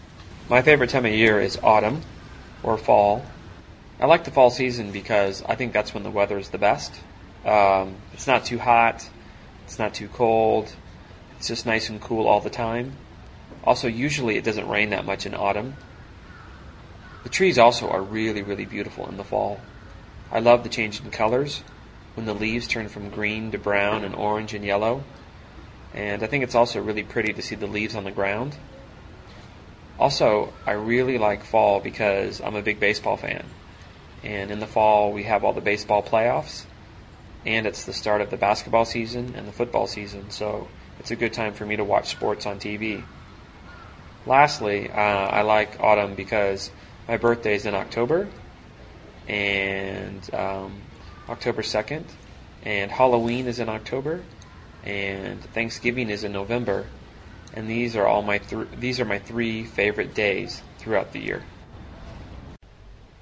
英语高级口语对话正常语速19:季节（MP3）